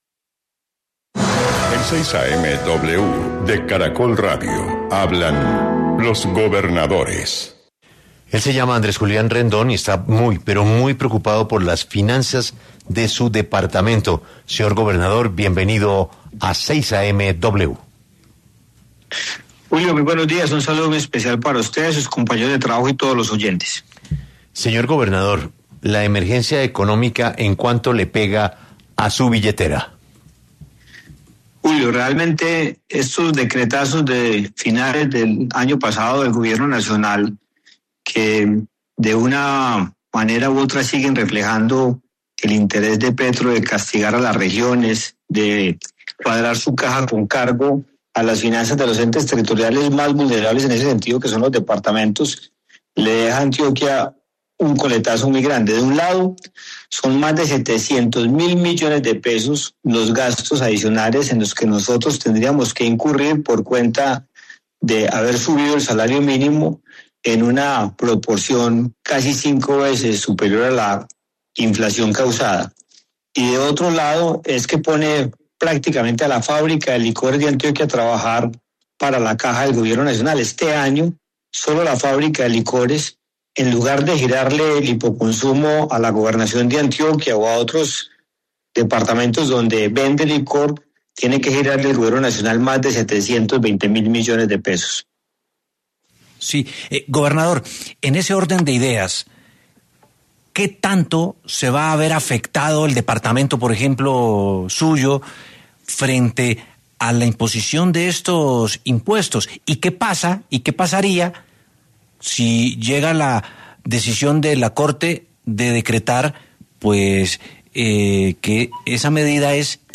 Andrés Julián Rendón, gobernador de Antioquia, estuvo en 6AM W hablando del impacto que tiene para las regiones la medida de emergencia económica del Gobierno Nacional.